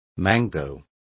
Complete with pronunciation of the translation of mango.